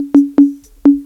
PERC LOOP1-L.wav